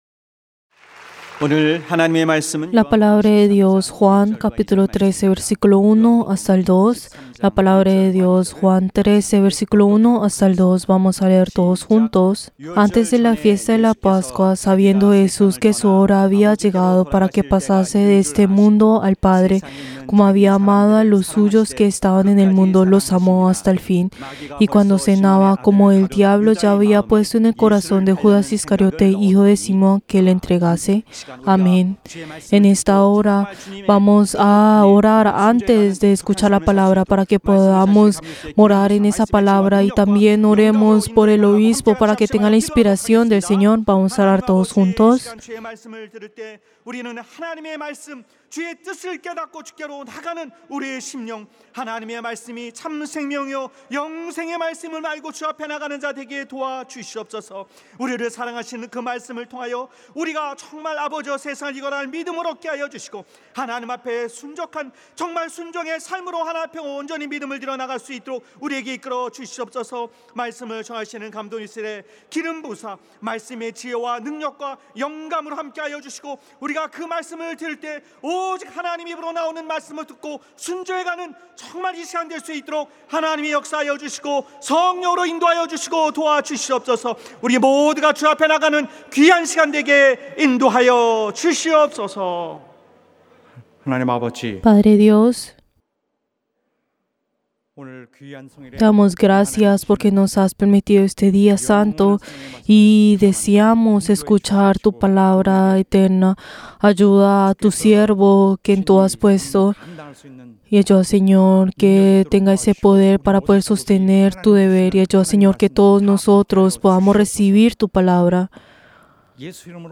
Servicio del Día del Señor del 20 de noviembre del 2022 El amor que ama a los suyos (Juan 13:1-2)